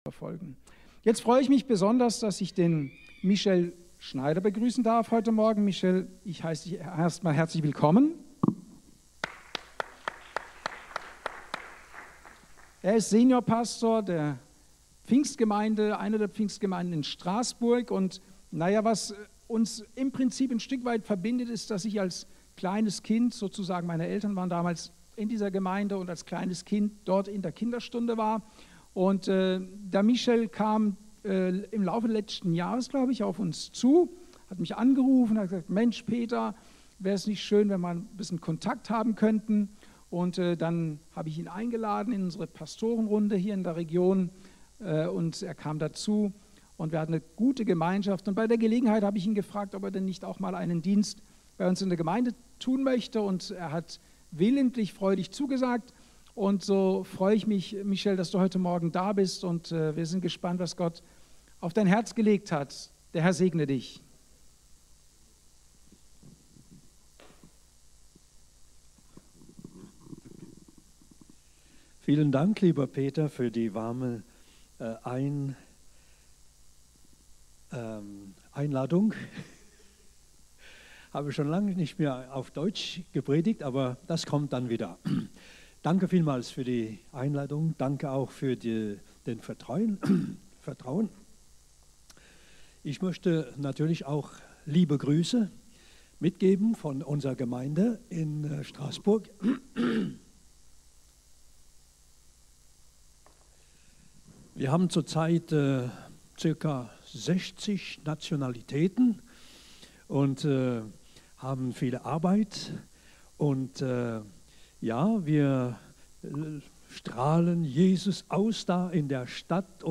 Datum: 17.11.2024 Ort: Gospelhouse Kehl